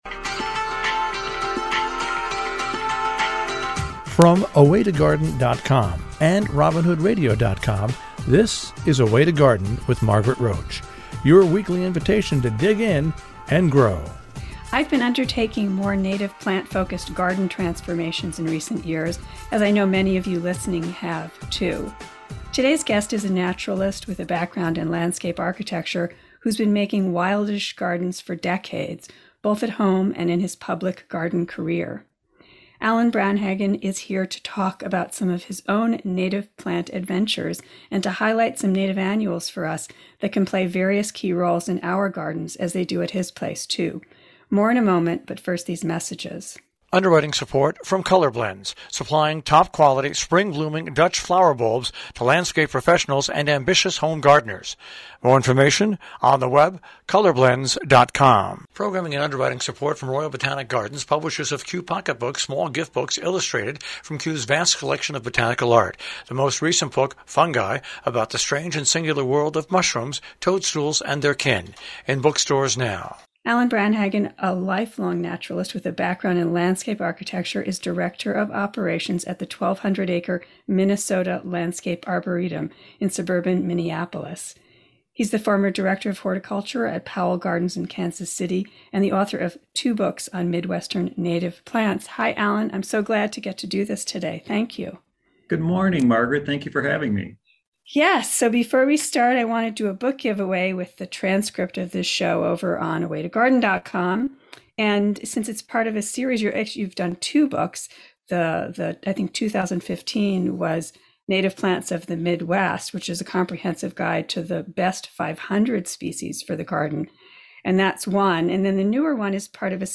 I’ve been undertaking more native-plant-focused garden transformations in recent years, as I know many of you listening have, too. Today’s guest is a naturalist with a background in landscape architecture who’s been making wildish gardens for decades, both at home and in his public-garden career.